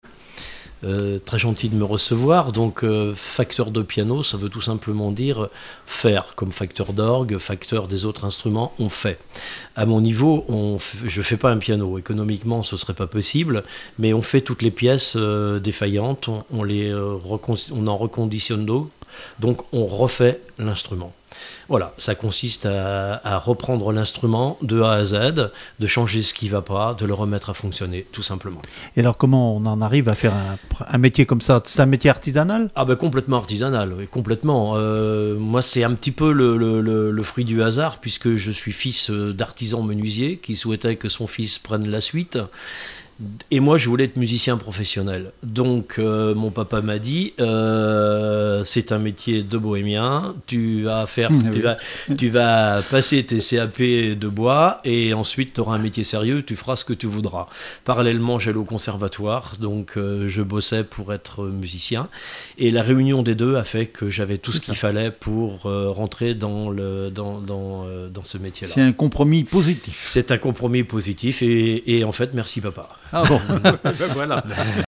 Emissions de la radio RCF Vendée
Témoignage sur le métier de facteur accordeur de piano
Catégorie Témoignage